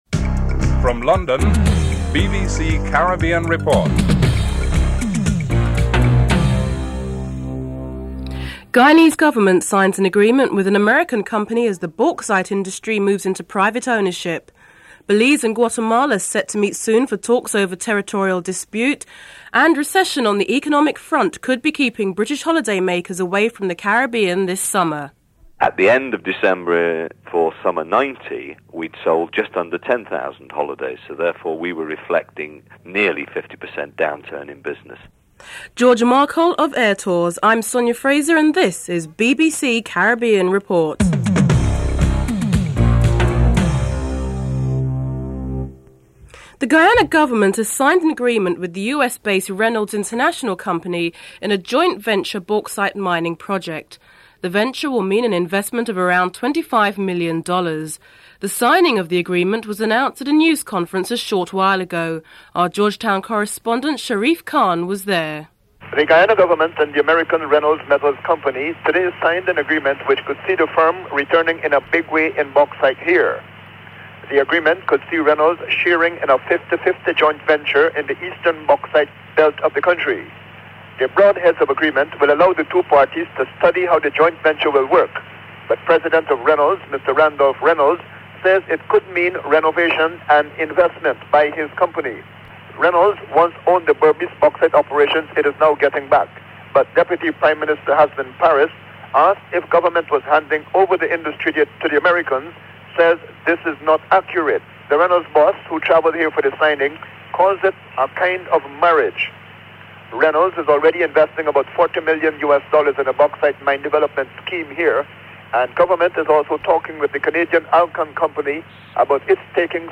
Further insights into these talks, the succession of land and access to the Caribbean are discussed by Manuel Esquivel, former Prime Minister of Belize. Following the Financial Market news, predictions are made of fewer British tourists to visit the Caribbean in 1990 due to the recession.